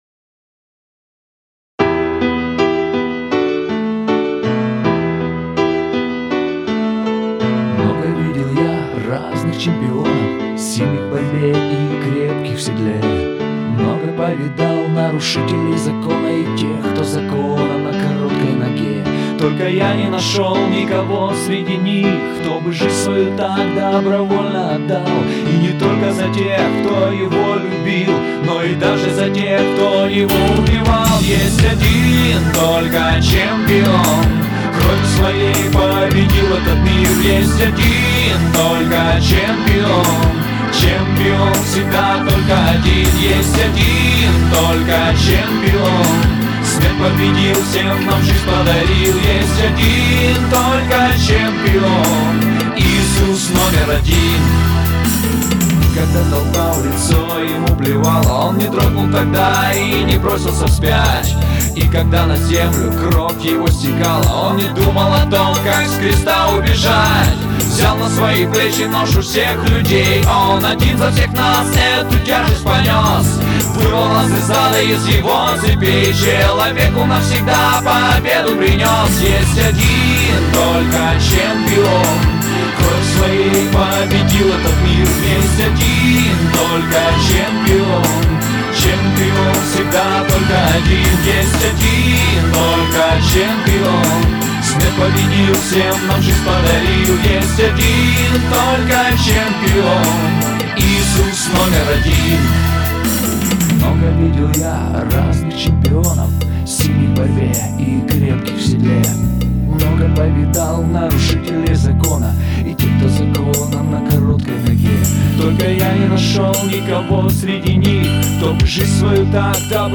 уникальная песня